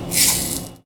R - Foley 52.wav